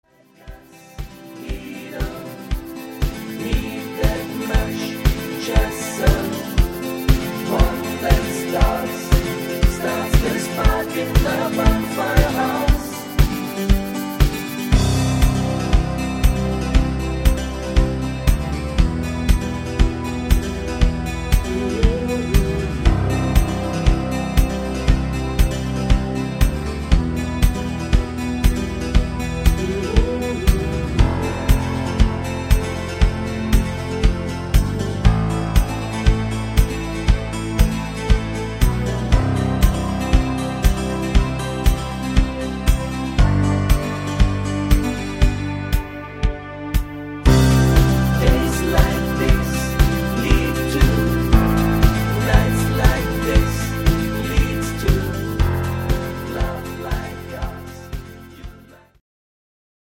Rhythmus  8 Beat
Art  Englisch, Pop